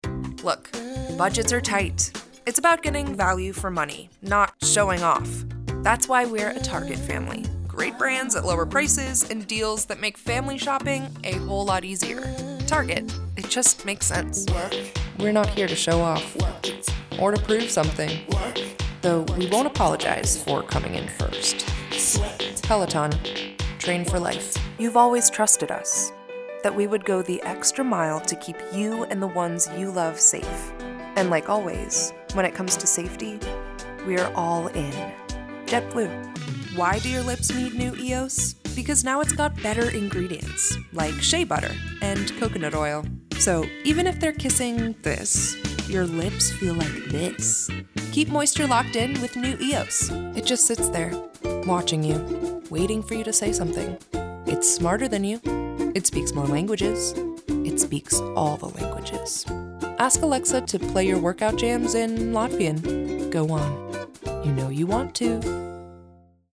A whimsical voice with a flair for drama, realness, and fantasy.
Commercial Voiceover Demo
English - British RP
British, Australian, Irish, English Southern
Young Adult